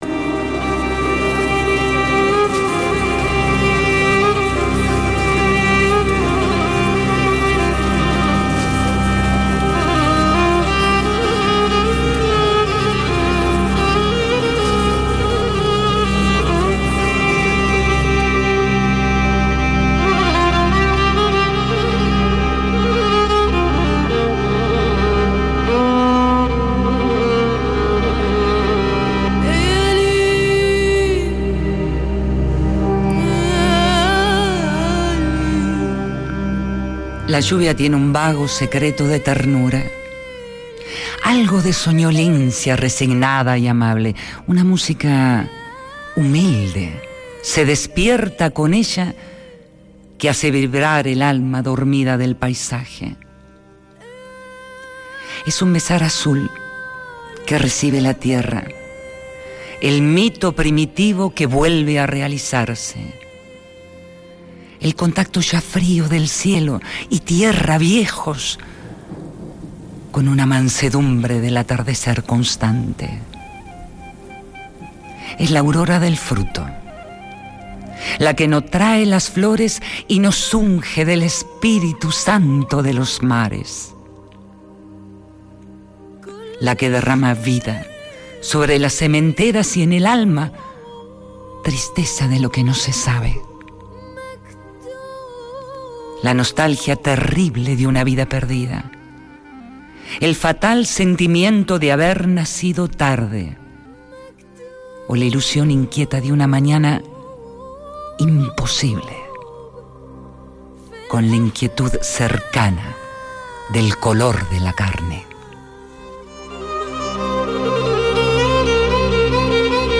Rapsodeando el poema Lluvia, de Federico García Lorca